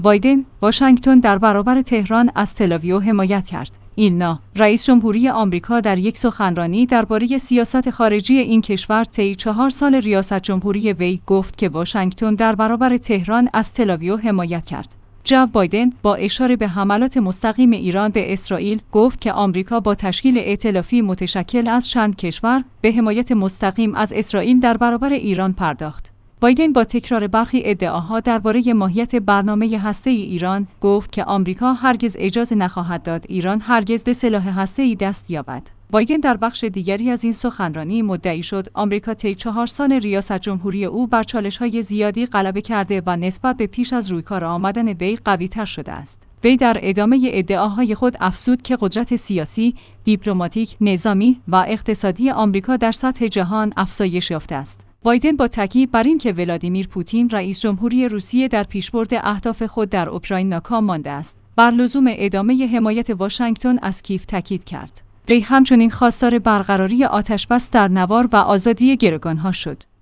ایلنا/ رئیس‌جمهوری آمریکا در یک سخنرانی درباره سیاست خارجی این کشور طی چهار سال ریاست‌جمهوری وی، گفت که واشنگتن در برابر تهران از تل‌آویو حمایت کرد.